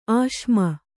♪ āśma